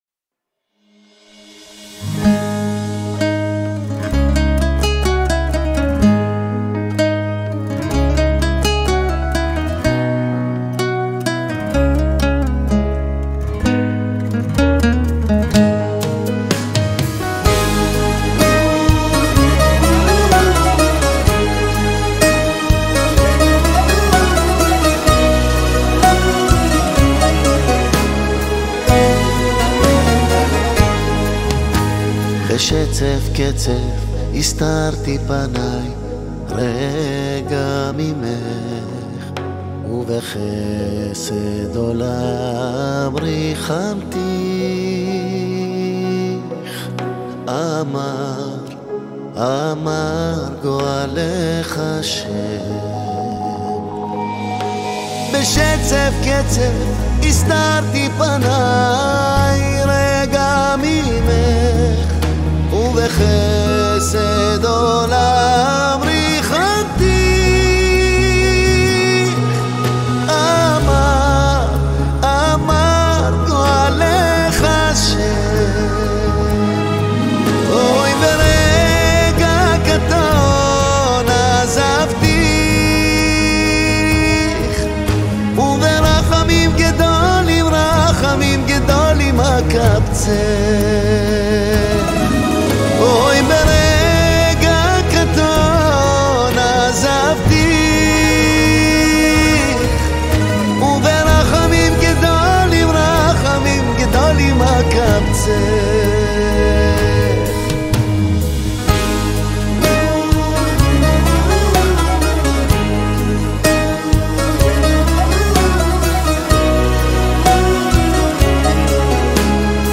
הזמר המזרחי
קאבר משובח לשיר הנוסטלגי
תופים
בס
קלידים ותיכנותים
גיטרות ובוזוקי